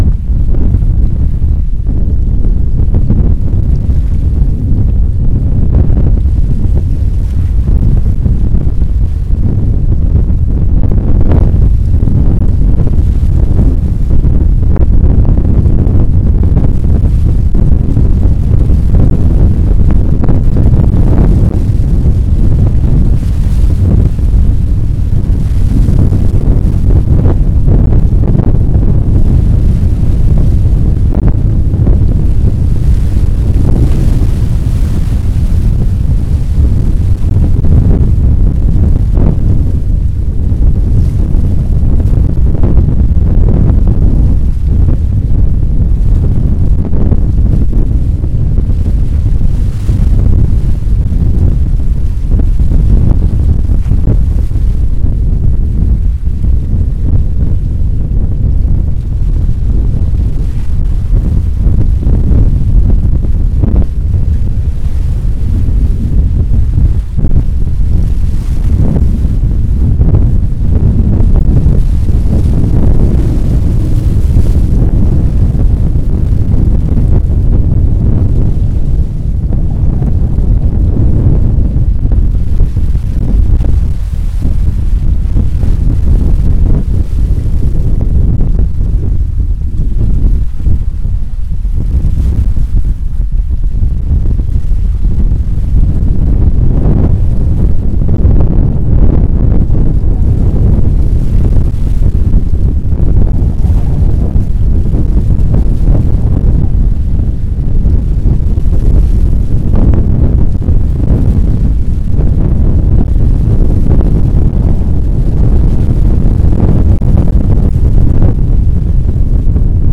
부산 필드 트립 중 기장의 어느 해안가에서 땅 쪽으로 불어오는 바람을 윈드스크린 없이 기록한 소리입니다. 특정 소리를 선명히 녹음하기 위해 필드 레코딩에서는 바람 소리는 대개 없는 듯 제거되어야 하지만, 오히려 바람 소리에 집중하면 그것은 노이즈처럼 들리고 끊임없는 움직임과 존재감을 알아챌 수 있습니다.